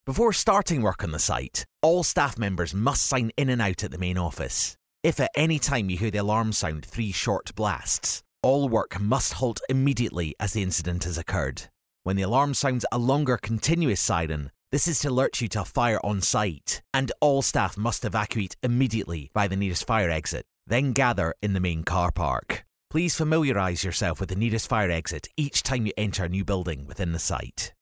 Records voiceovers in: Scottish
E-Learning